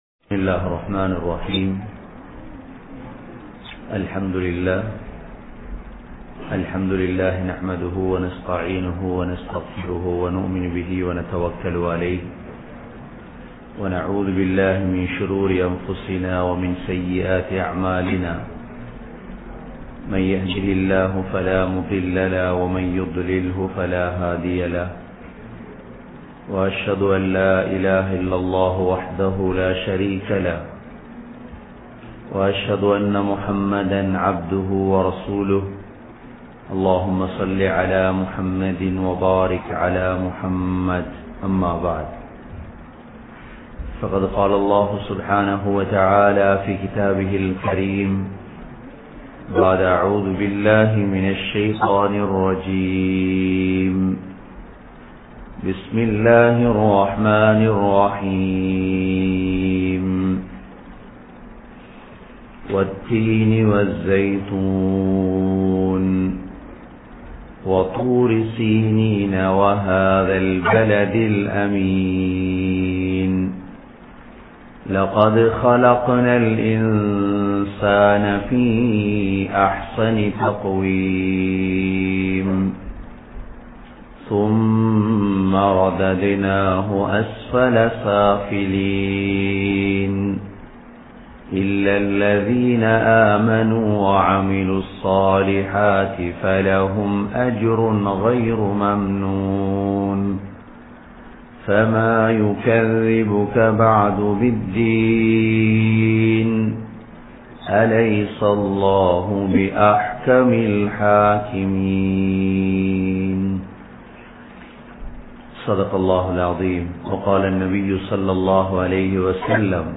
Nabi(SAW)Avarhalin Thanmaihal | Audio Bayans | All Ceylon Muslim Youth Community | Addalaichenai